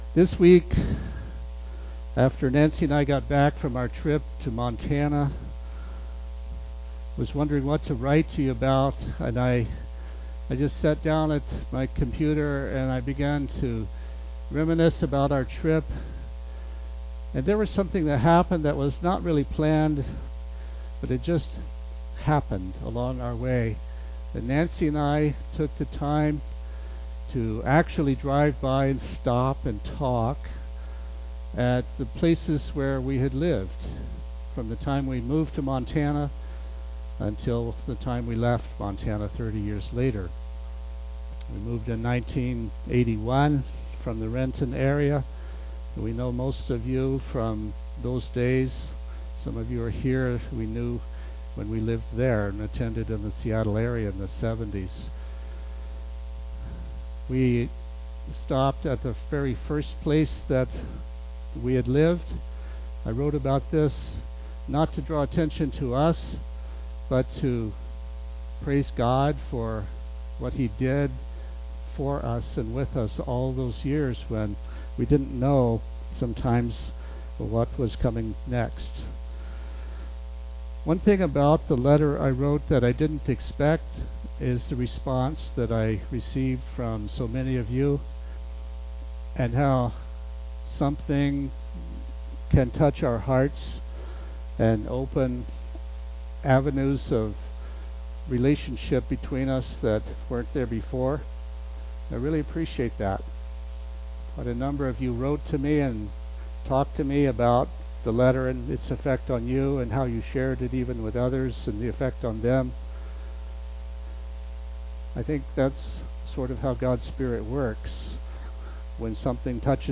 Sermons
Given in Tacoma, WA